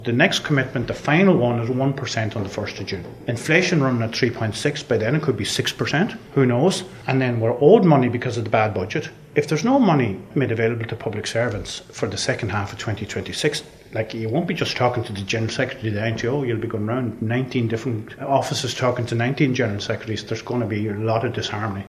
Speaking in Killarney in county Kerry